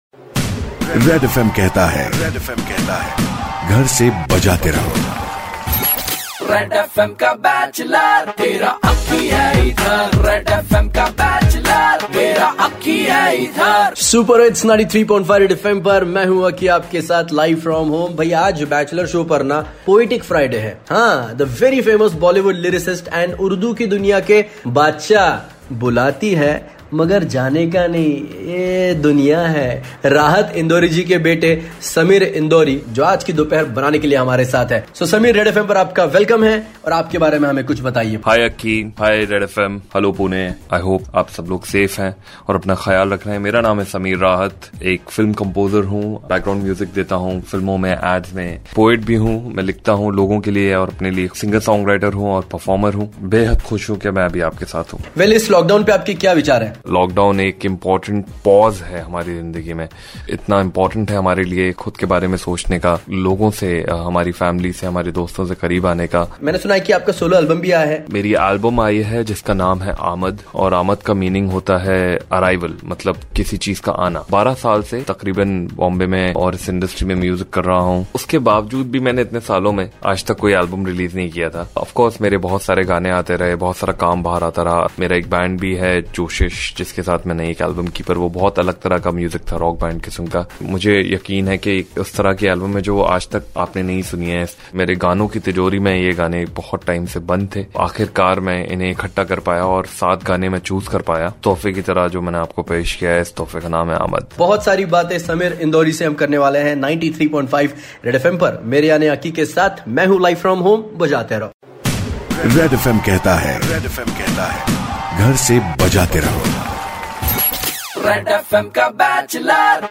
Music talk